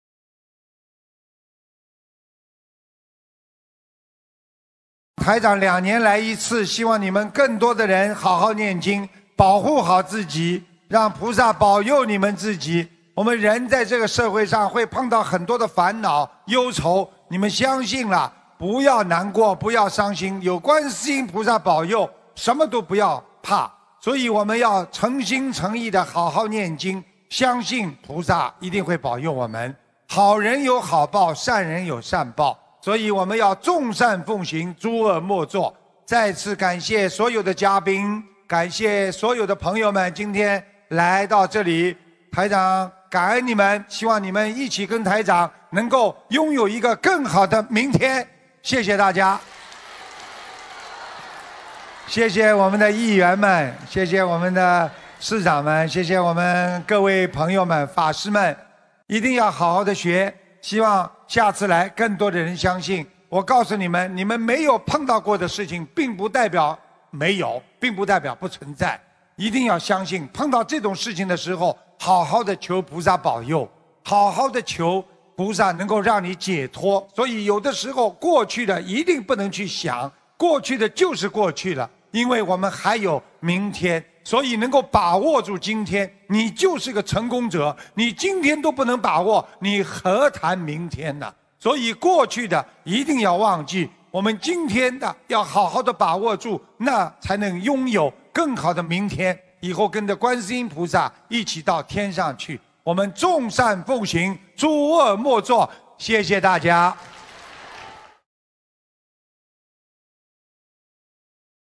2018年10月14日美国纽约法会感人结束语-经典开示节选